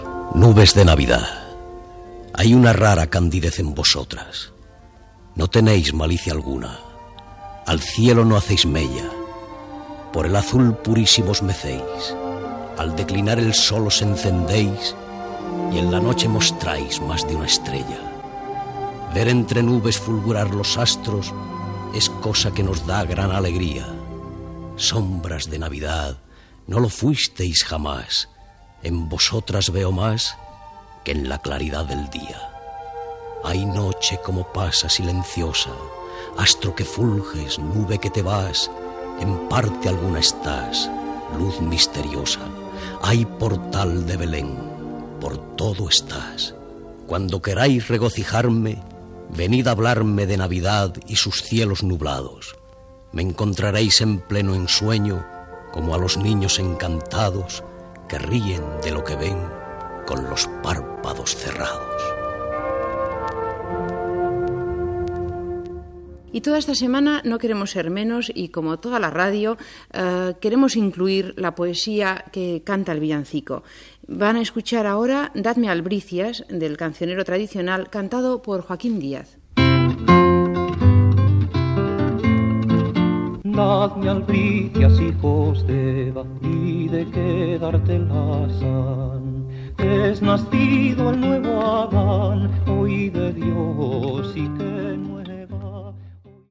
Lectura del poema "El núvols de Nadal", de Joan Maragall, en castellà i una nadala del folklore castellà